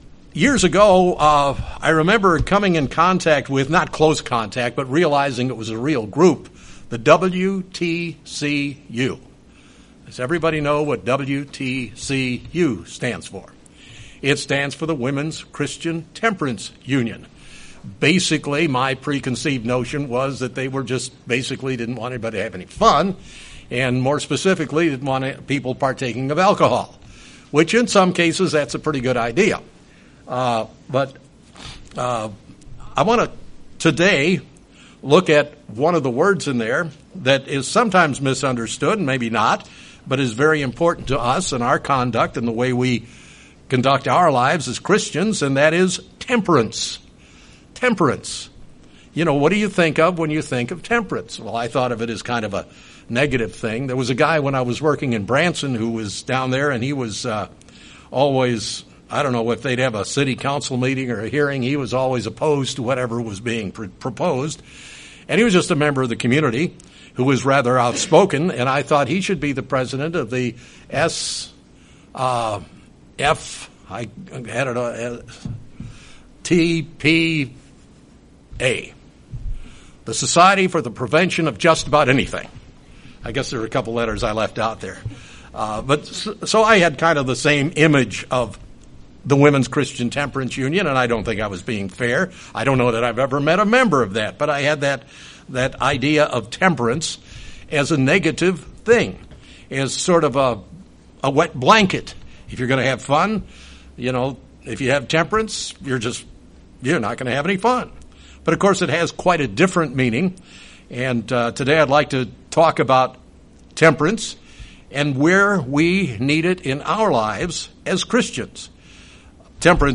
A sermon looking into the topic of termperance within a Christian's life.